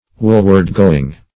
Search Result for " woolward-going" : The Collaborative International Dictionary of English v.0.48: Woolward-going \Wool"ward-go`ing\, n. A wearing of woolen clothes next the skin as a matter of penance.